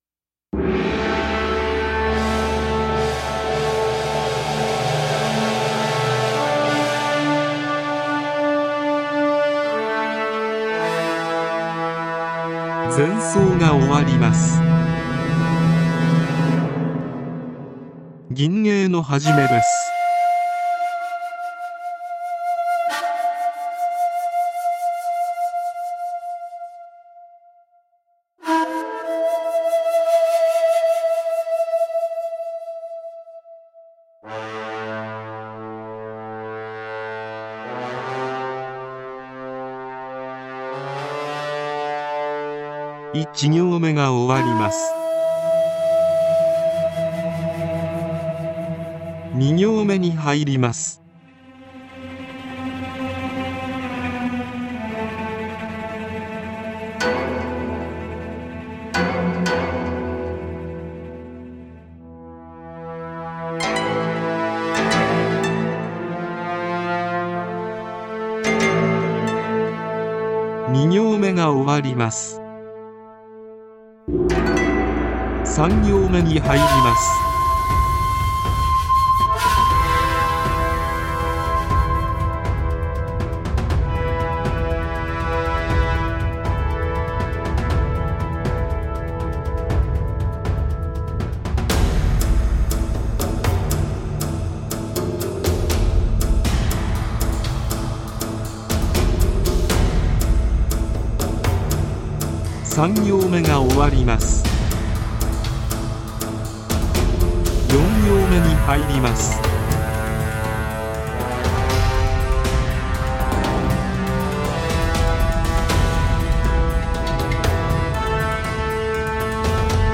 ガイド音声は目安ですので、吟法や詩によって多少前後しても大丈夫です。
伴奏曲
ガイド音声入
絶句伴奏曲